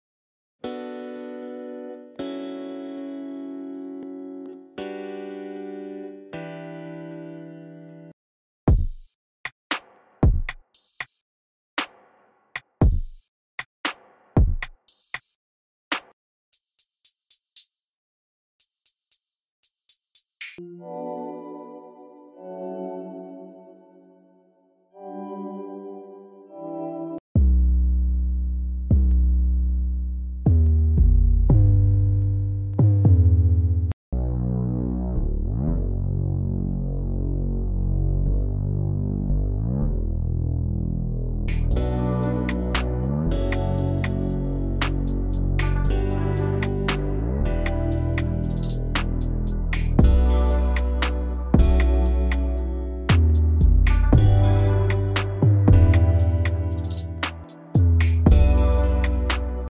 in FL Studio